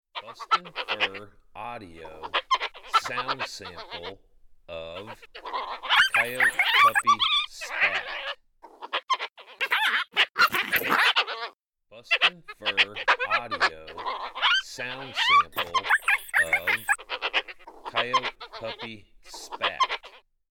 BFA Coyote Puppy Spat
Young Coyote pups fighting over food. Excellent territorial sound.
BFA Coyote Puppy Spat Sample.mp3